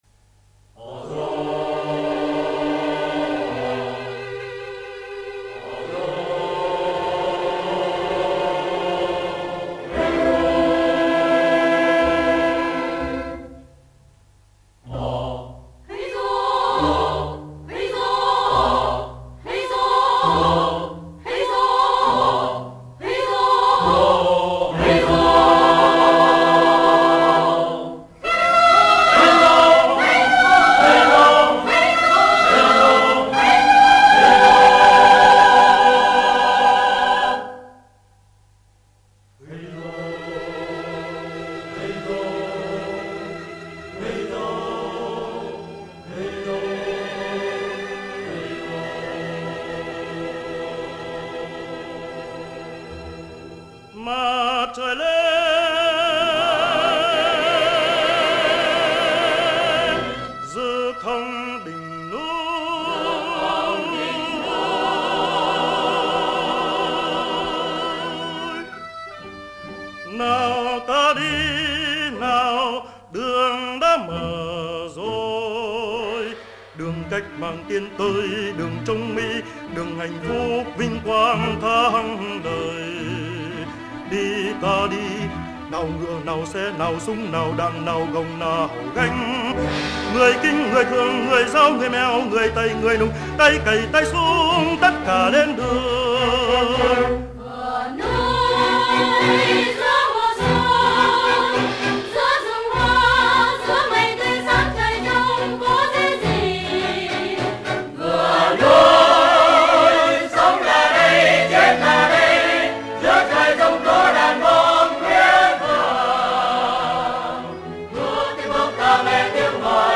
Coro, épico, nuevo typo de música vocal